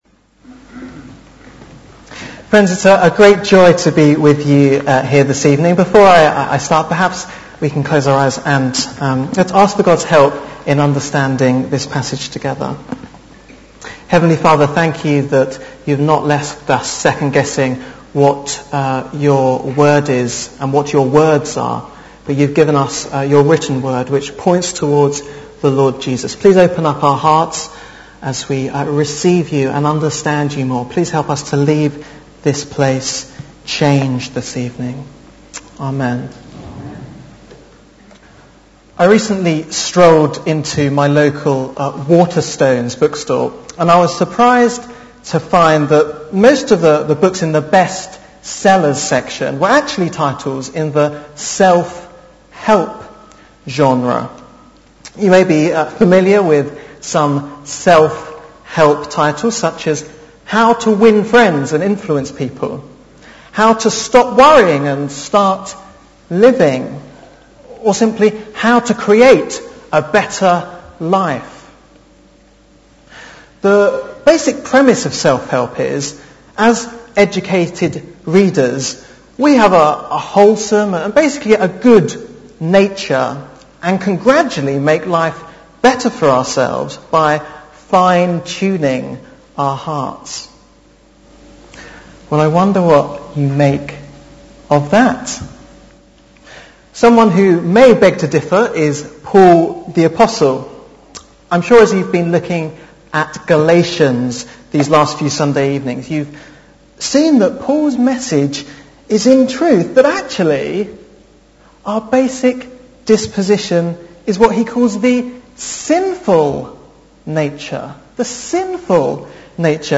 Living by the Spirit - Cambray Baptist Church